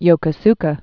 (yōkə-skə, yôkô-skä)